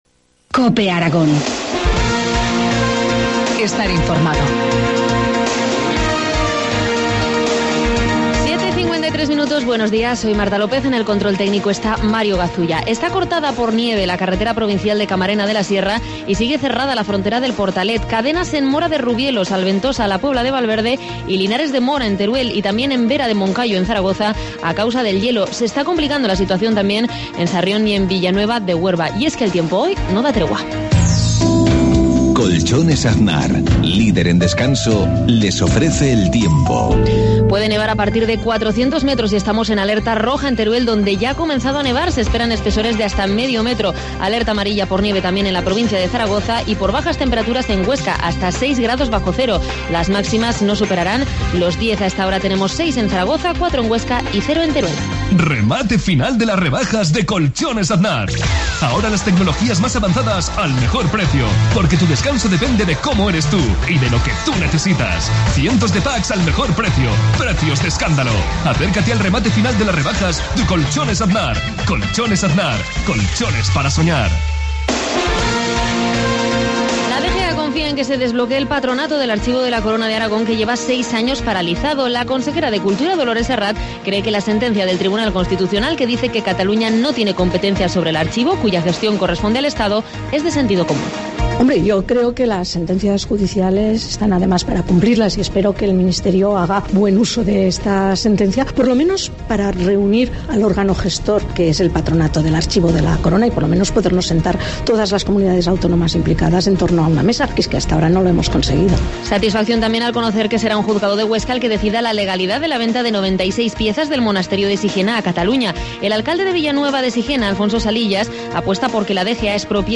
Informativo matinal, jueves 28 de febrero, 7.53 horas